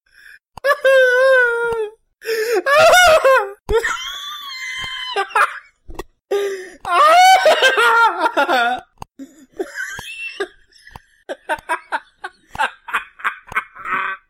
Risada
Risada do youtuber